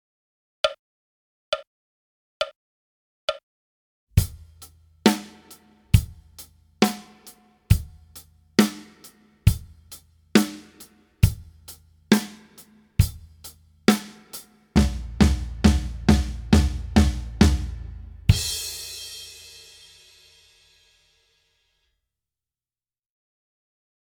Etude pour Batterie